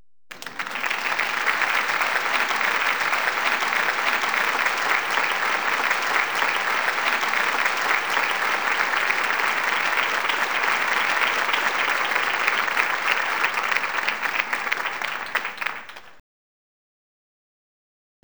בקשות קליפים / לופים / אפקטים, ועוד - סאונד של מחיאת כף, כבר חפשתי
חפשתי ברחבי הרשת סאונד של מחיאת כף או מחיאות כפיים בקצב, להורדה חינם, לא מצאתי.